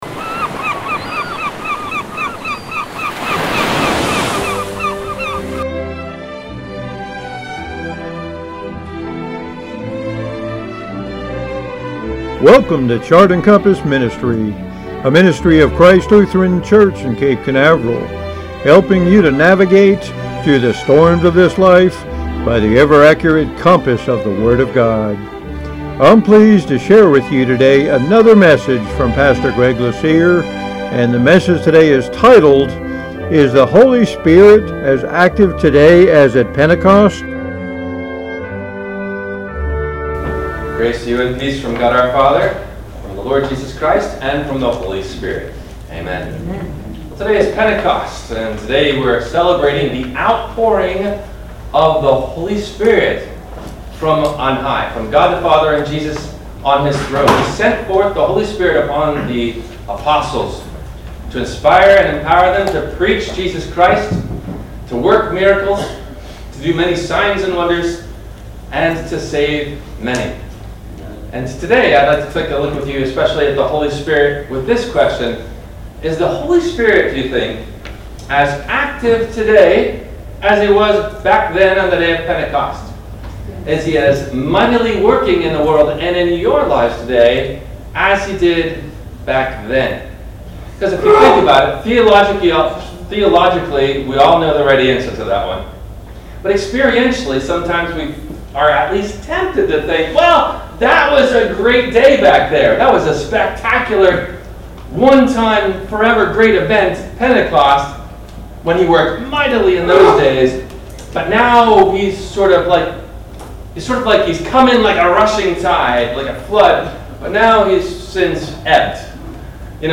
Is The Holy Spirit as Active Today as at Pentecost? – WMIE Radio Sermon – May 27, 2024
No questions asked before the Sermon message.